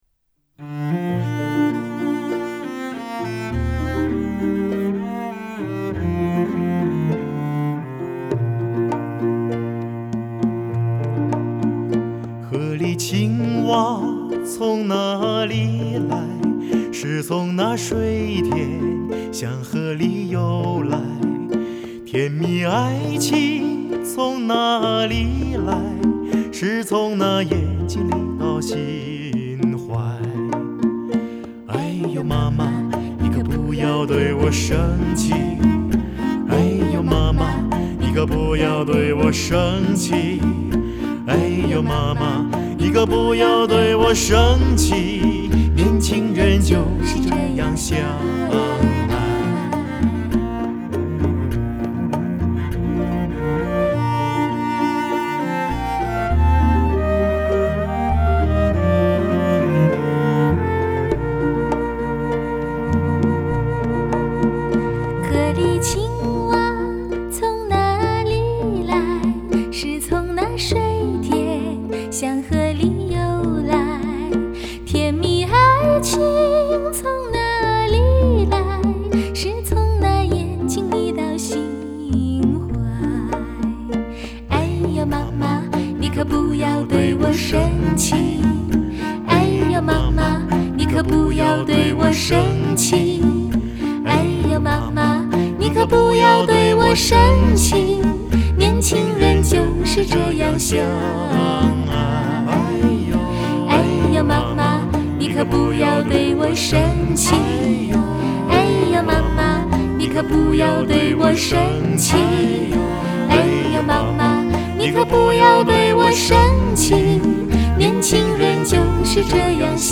全国首张DSD直录SACD，用普通的音响也能感受到高级音响的感觉和效果。